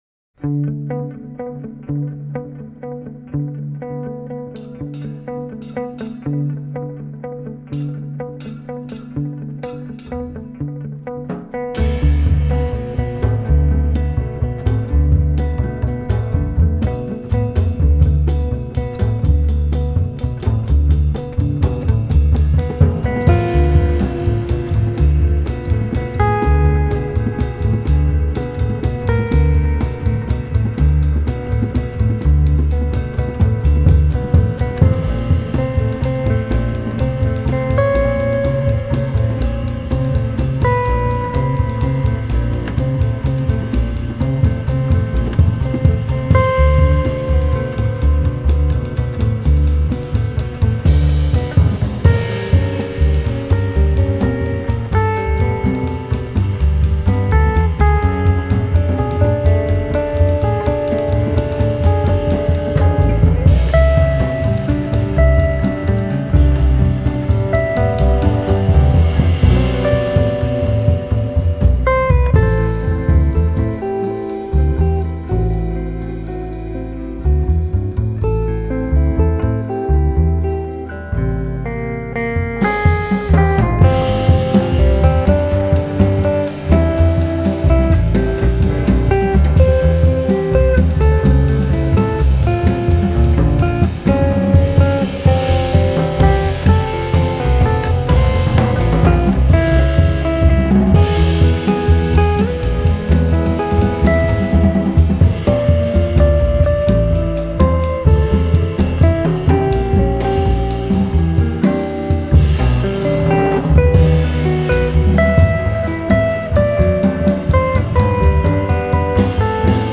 Recorded in studio after a European tour
modern jazz
guitar
bass
drums